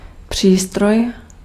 Ääntäminen
IPA: [ma.ʃin]